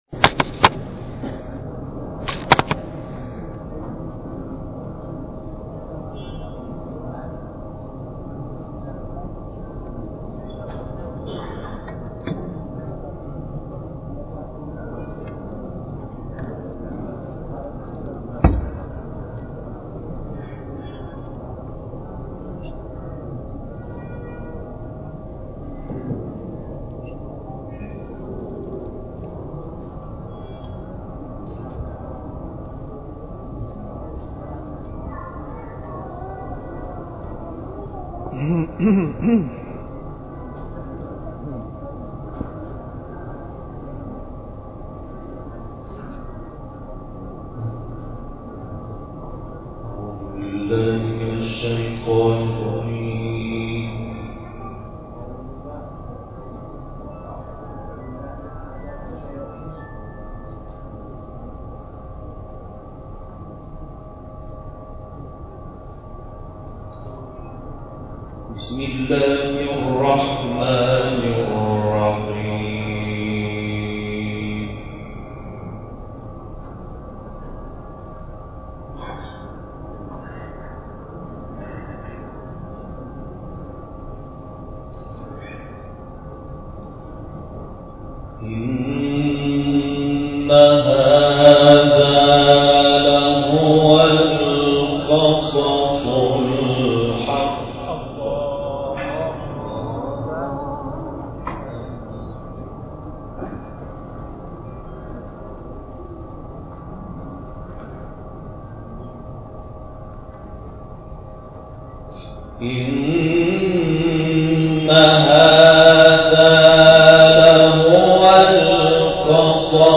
گروه فعالیت‌های قرآنی: تلاوت کمتر شنیده شده محمود شحات انور که در شهر قزوین اجرا شده است، ارائه می‌شود.